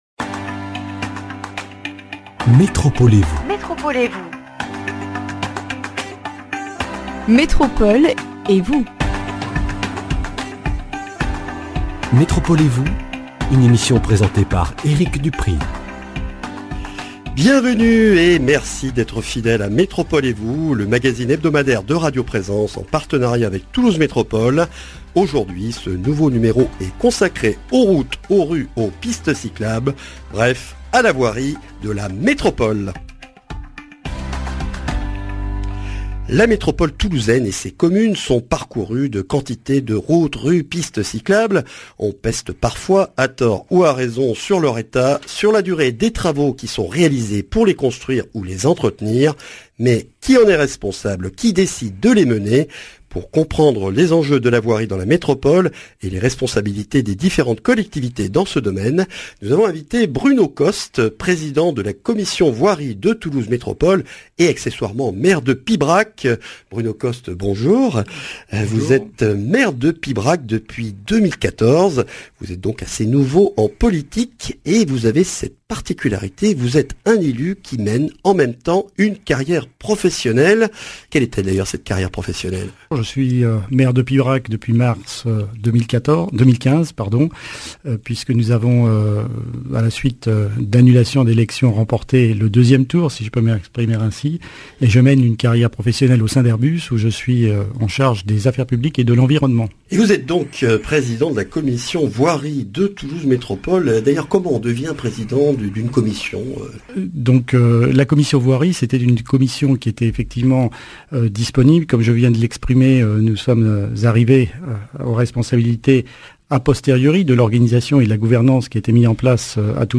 Un échange avec Bruno Costes, maire de Pibrac, Président de la commission Voirie de Toulouse Métropole, pour connaître les responsabilités et le domaine d’intervention de Toulouse Métropole dans la construction et l’entretien des rues, routes et pistes cyclables de l’agglomération toulousaine.